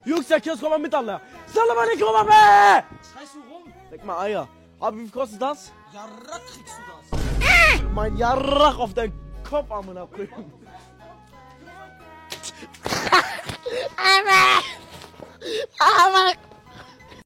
yarakkk bekommst du Meme Sound Effect
Category: Meme Soundboard